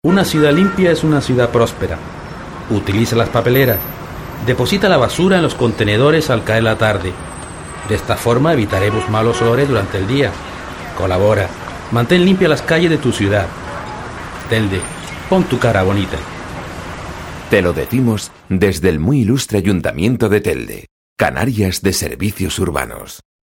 Voces Masculinas